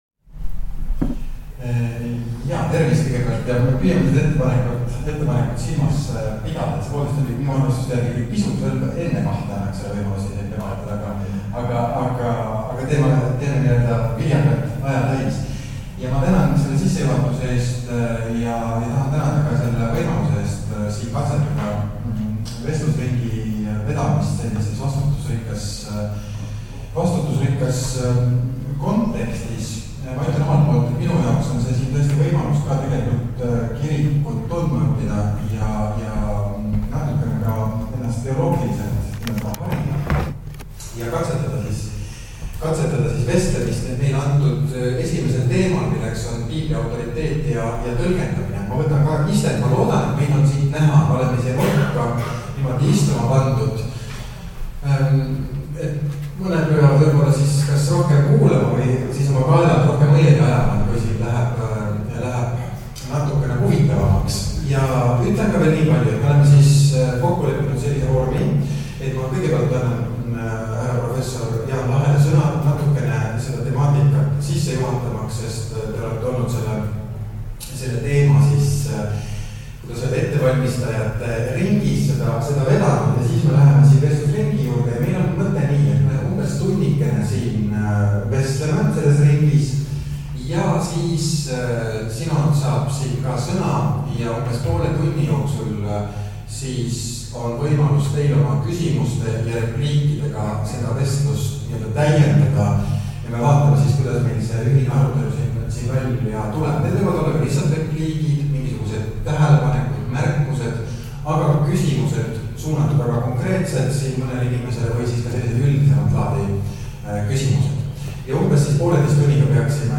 Paneelvestlused | EELK
Kuula piiskopkondade 2025. aasta sinoditel toimunud paneelvestlusi Piibli autoriteedi ja tõlgendamise teemadel.